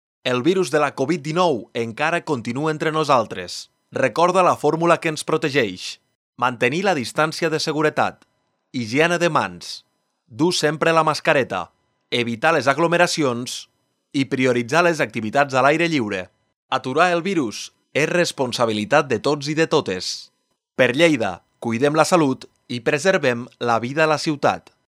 Un vehicle de Protecció Civil municipal recorre la ciutat emetent un missatge recordatori sobre les mesures bàsiques de prevenció de la COVID-19
missatge-recordant-les-mesures-contra-la-covid-19